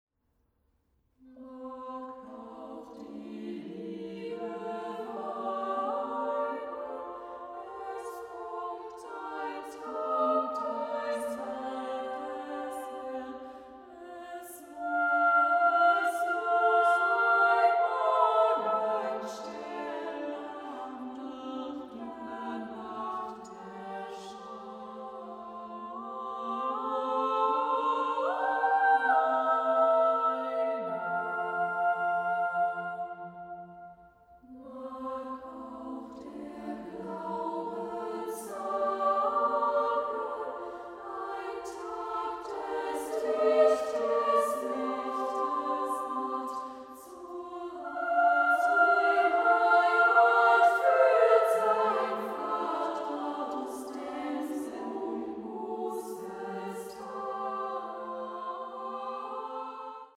Works for Women’s and Children’s Choir and Solo Songs